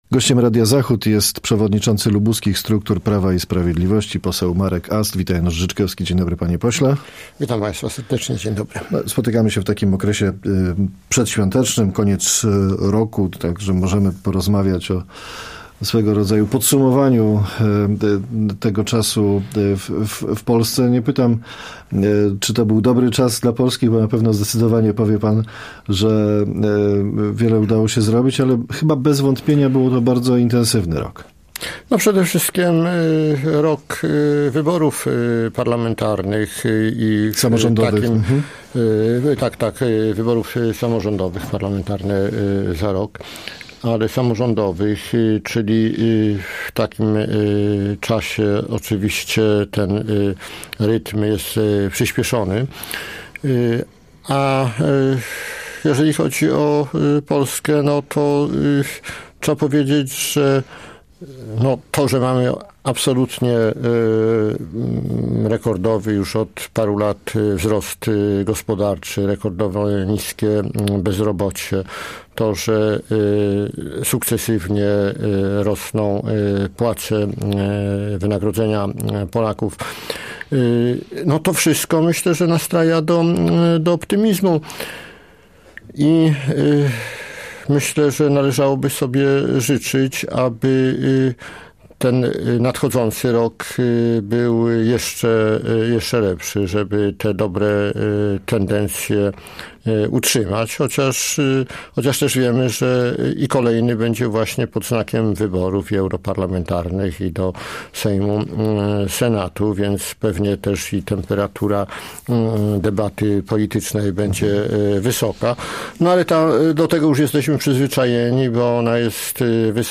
Z posłem PiS, przewodniczącym partii w regionie rozmawia